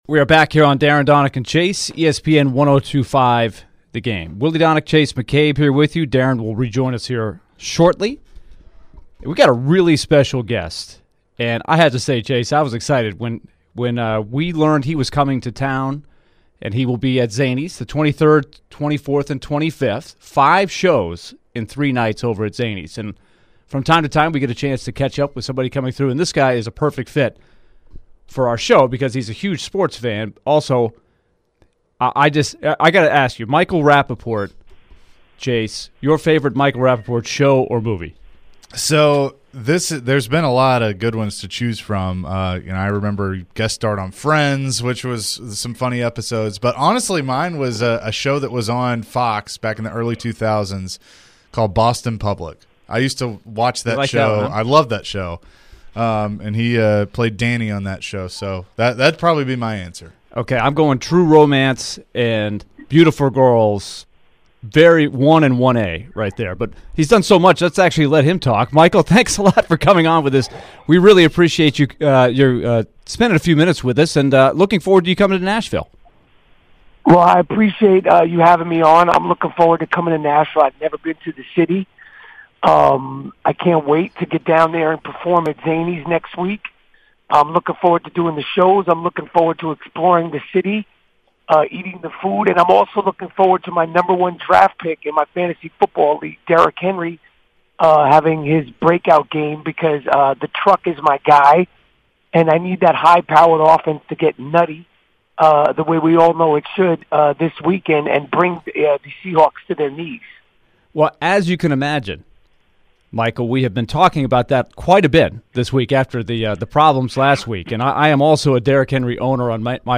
Actor and Comedian Michael Rapaport joined the show to discuss his upcoming Nashville appearance, sports fandoms and more!